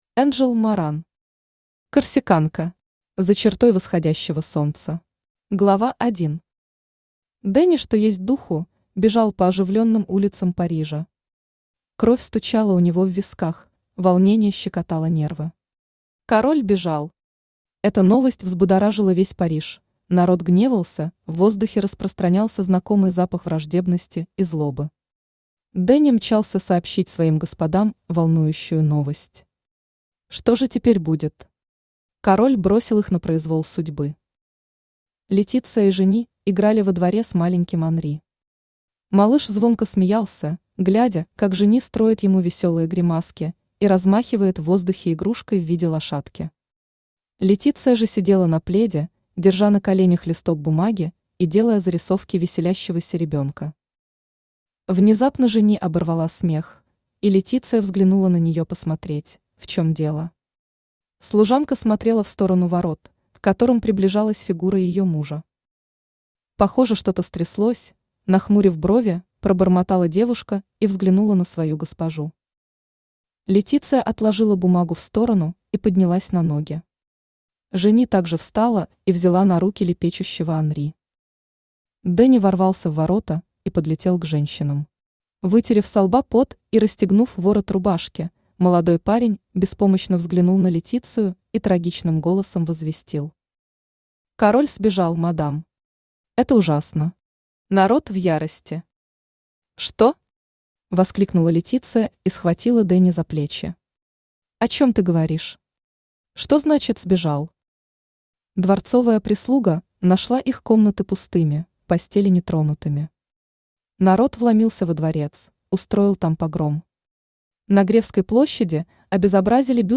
Аудиокнига Корсиканка. За чертой восходящего солнца | Библиотека аудиокниг
За чертой восходящего солнца Автор Энджел Моран Читает аудиокнигу Авточтец ЛитРес.